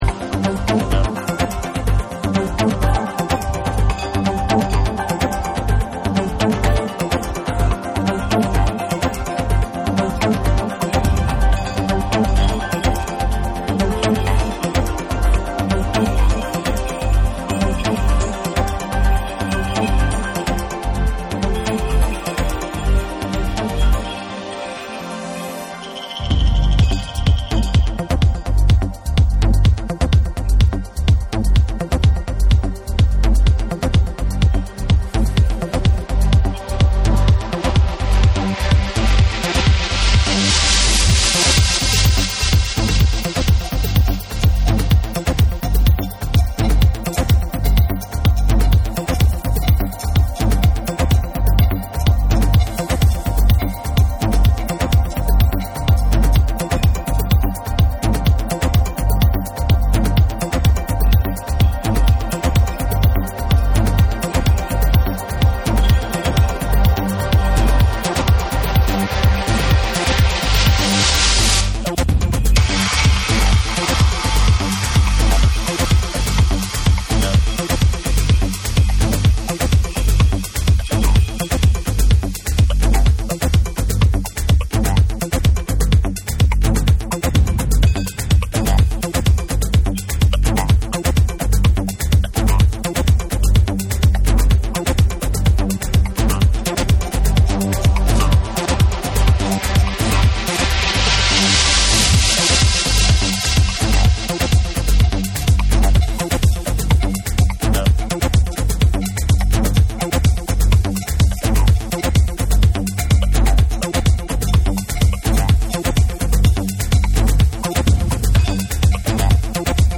JAPANESE / TECHNO & HOUSE / ORGANIC GROOVE